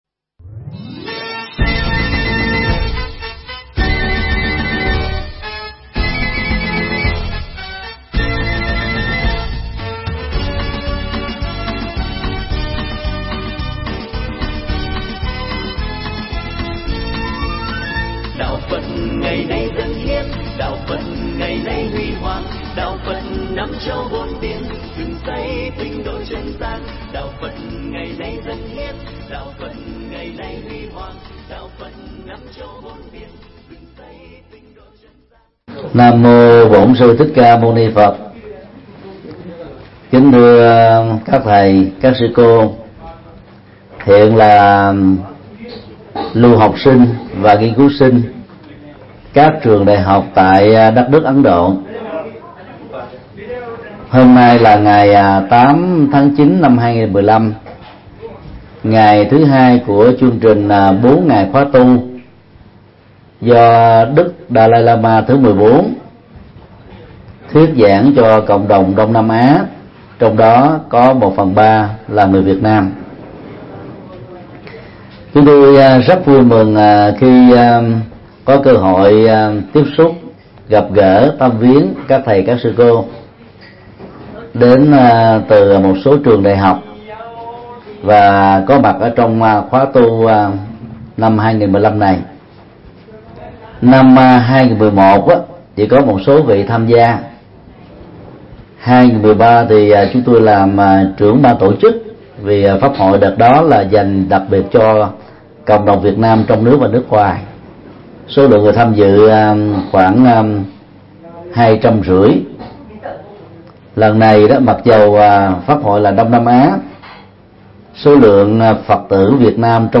Bài Giảng
Giảng cho Tăng Ni sinh đang du học tại Ấn Độ nhân Pháp hội Dharamsala 2015